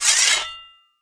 Index of /cstrike/sound/weapons
ninja_deploy1.wav